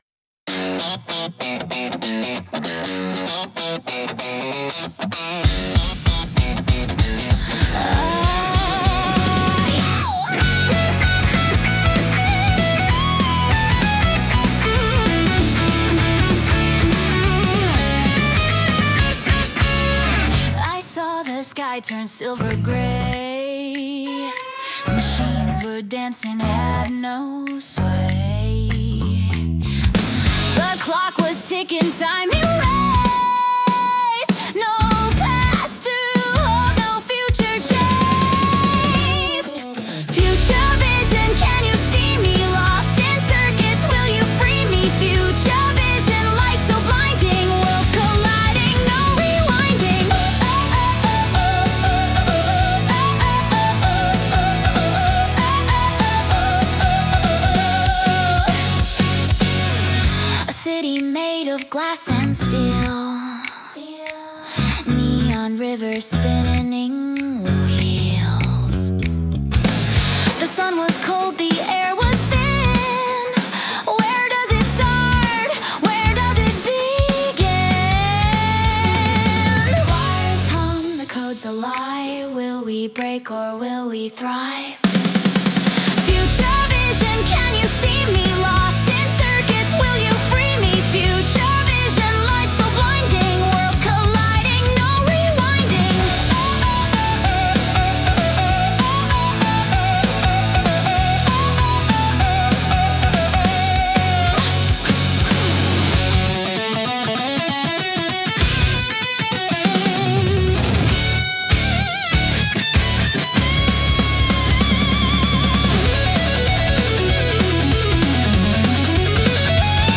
アニメ音楽は、日本のアニメ主題歌をイメージしたジャンルで、ポップでキャッチーなメロディとドラマチックな展開が特徴です。
明るくエネルギッシュな曲調から感動的なバラードまで幅広く、視聴者の感情を引き立てる要素が豊富に詰まっています。